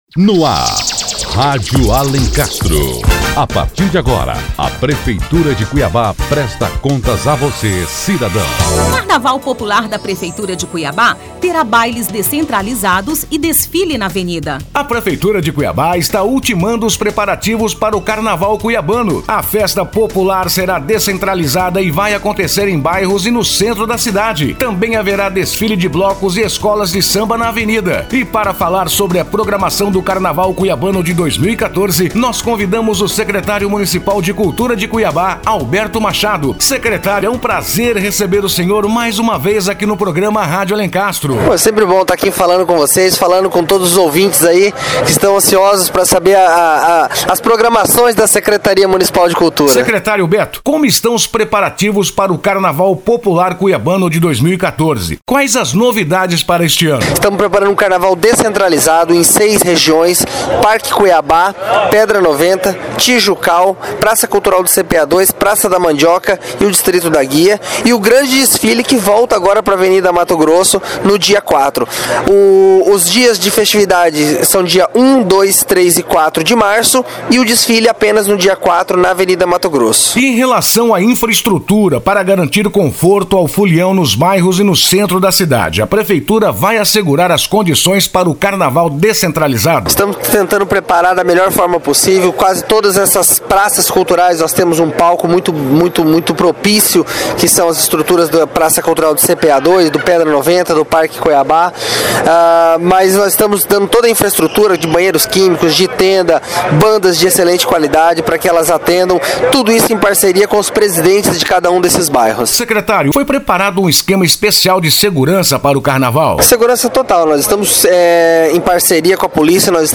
O Secretário Municipal de Cultura, Alberto Machado, fala sobre as programações do carnaval deste ano.